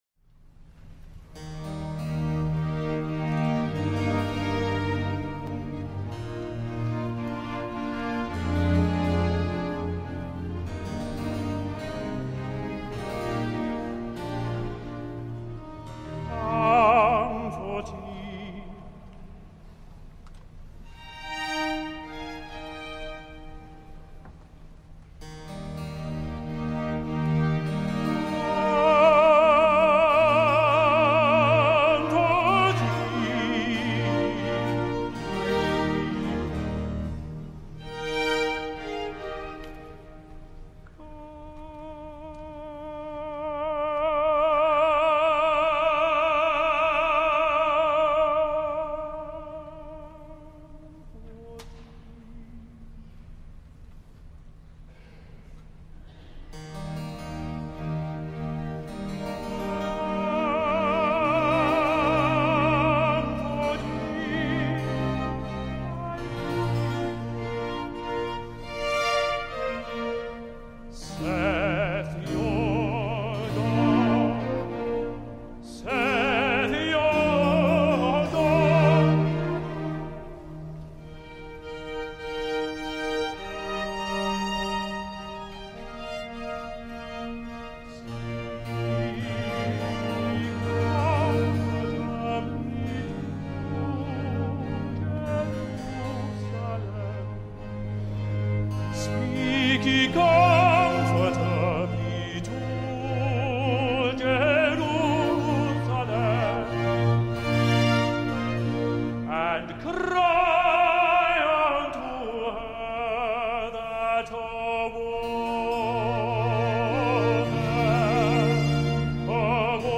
He made his debut in 1985 with Kent Opera in Mozart's Bastien und Bastienne, but he was primarily to be a concert tenor, specializing in lute-accompanied medieval and Renaissance music, in German and French lieder and in oratorio, with successful appearances in many European countries.